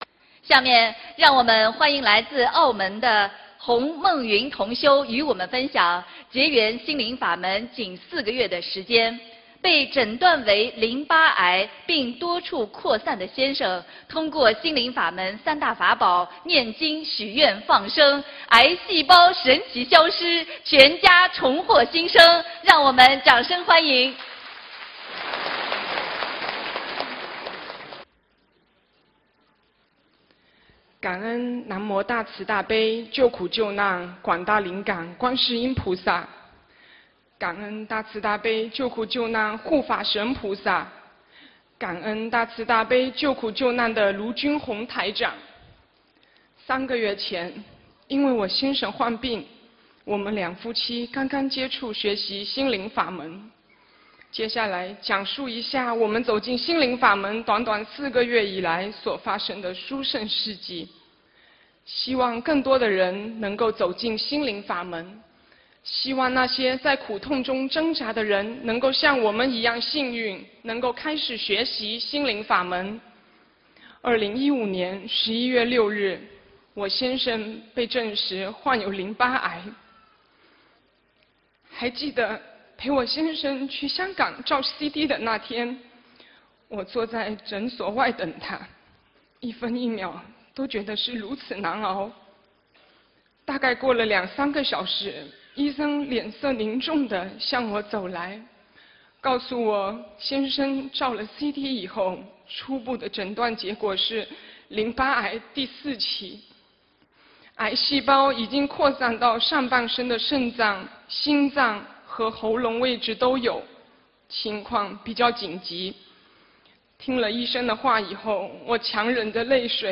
音频：先生晚期淋巴癌癌症多处扩散学佛后痊愈--分享201602澳门